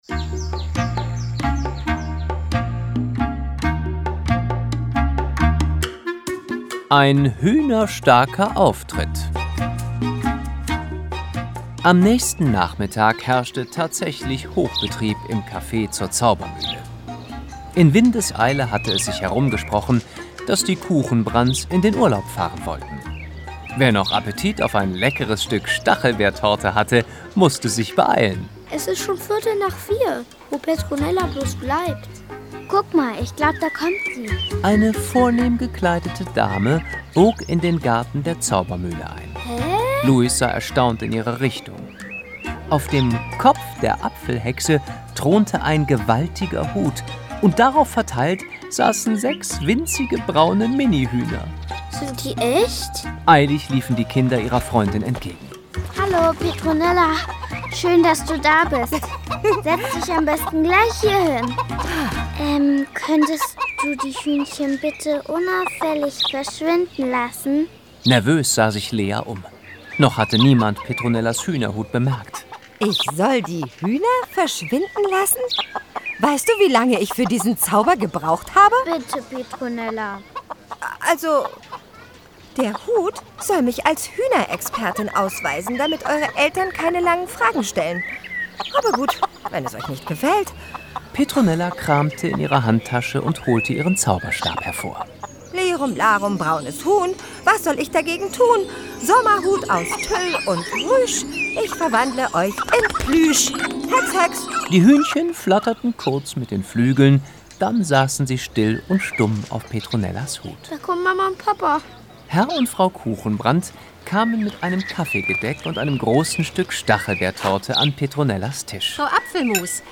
Petronella Apfelmus - Die Hörspielreihe Teil 6 - Schnattergans und Hexenhaus. Hörspiel.